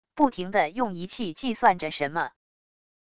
不停地用仪器计算着什么wav音频